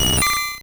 Cri de Chenipan dans Pokémon Rouge et Bleu.